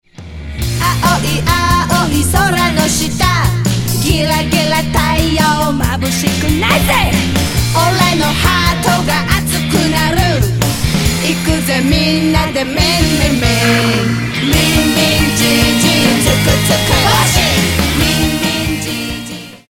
（ダンス）試聴する